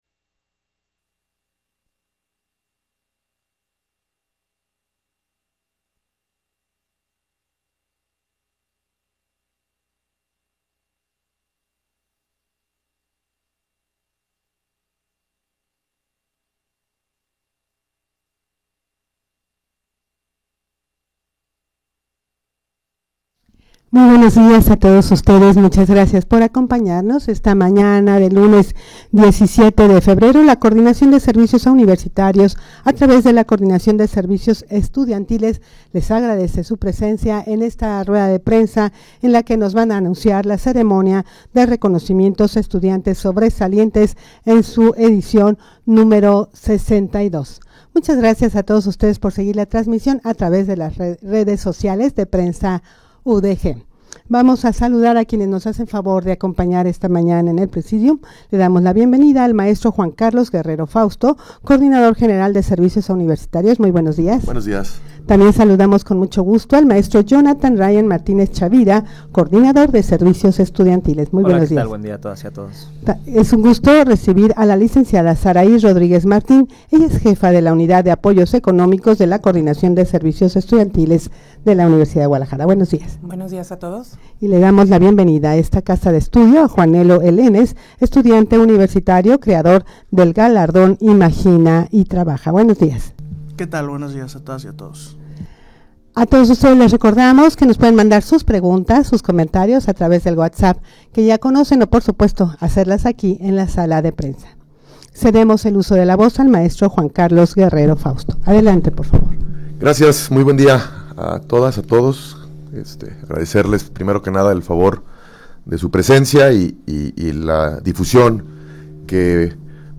rueda-de-prensa-para-anunciar-la-ceremonia-de-reconocimientos-a-estudiantes-sobresalientes.mp3